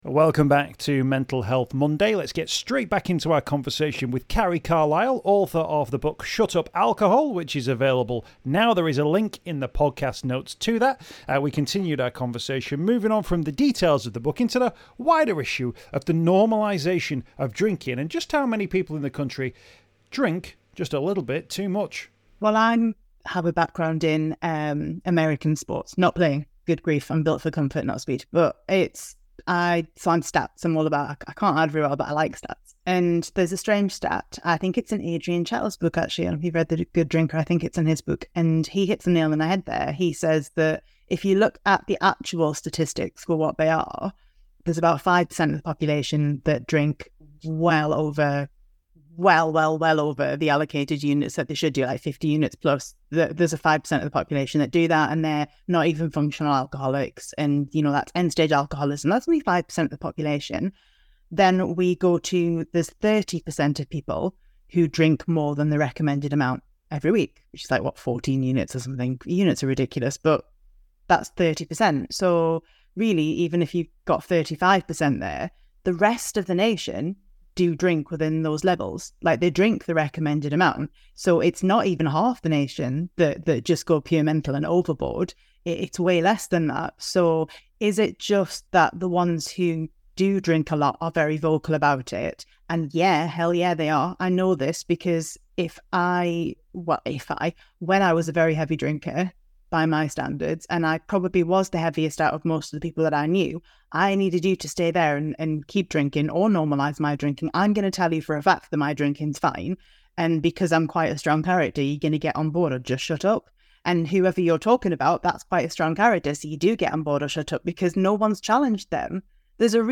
Join us for part two of our podcast conversation